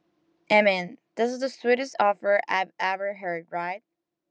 Latin_American_Speaking_English_Speech_Data_by_Mobile_Phone